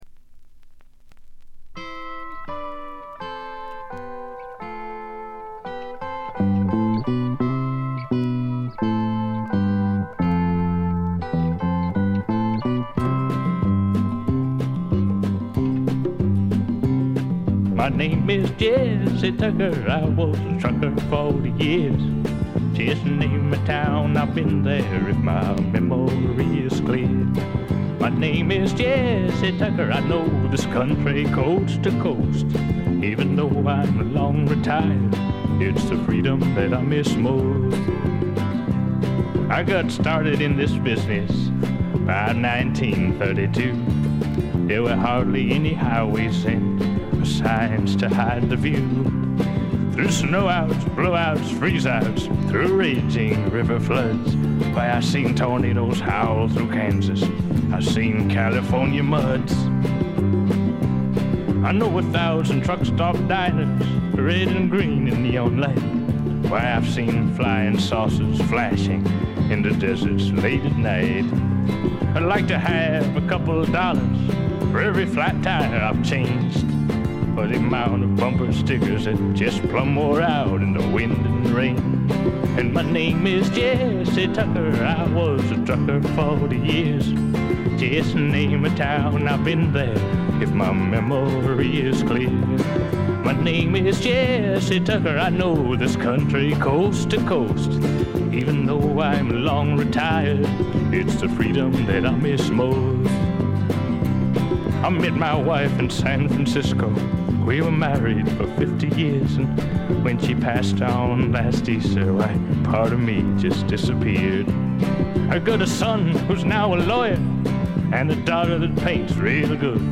部分試聴ですがごくわずかなノイズ感のみ。
70年代シンガー・ソングライター・ブームが爛熟期を迎え、退廃に向かう寸前に発表されたフォーキーな名作です。
シンガー・ソングライター基本盤。
試聴曲は現品からの取り込み音源です。
Guitar
Piano
Drums
Bass
Background Vocal